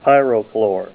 Say PYROCHLORE Help on Synonym: Synonym: ICSD 27815   PDF 17-746